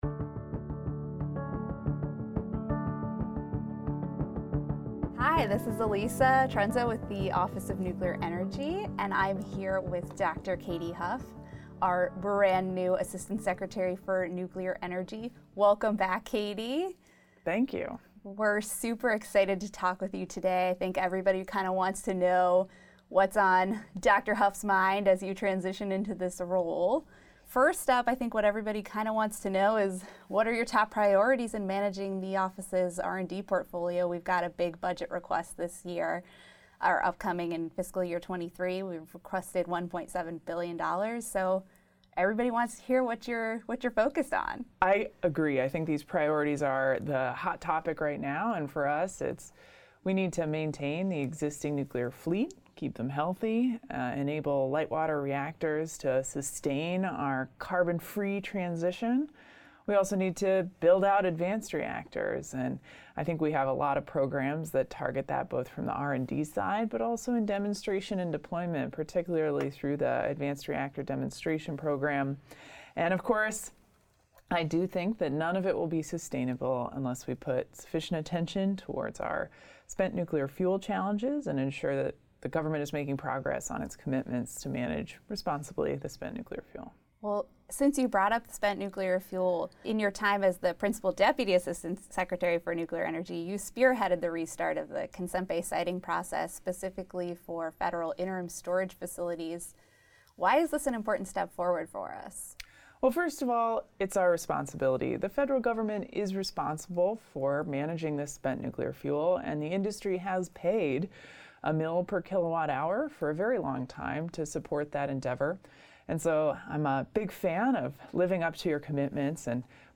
Q&A: Assistant Secretary Dr. Kathryn Huff Discusses Top Priorities for Nuclear Energy